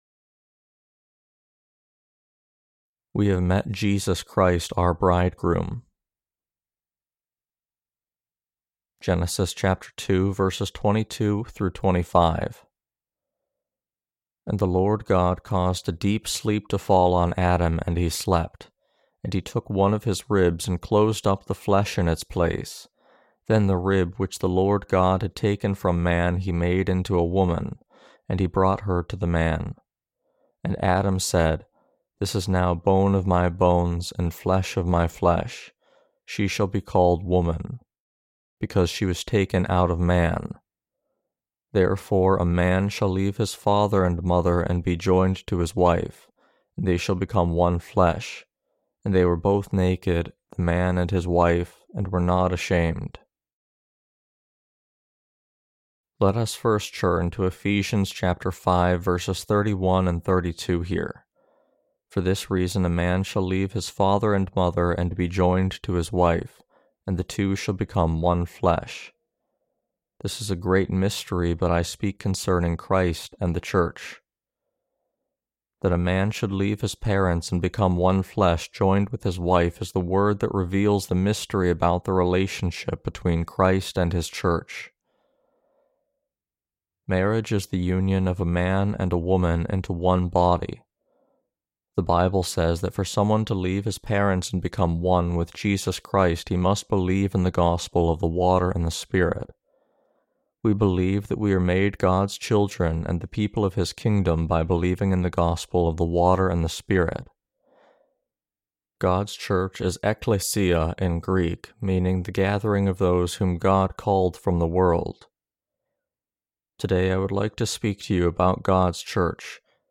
Sermons on Genesis (II) - The Fall of Man and The Perfect Salvation of God Ch2-3.